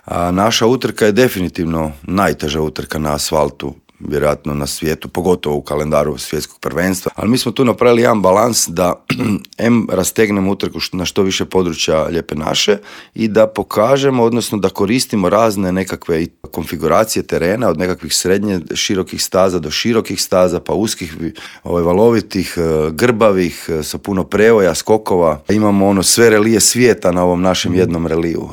Osvrnuo se u intervjuu Media servisa na značaj utrke, utjecaj na gospodarstvo, stazu i na sve popratne događaje.